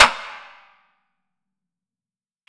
A PROPER SLAP
BA-BellySlap-Proper-Slap.wav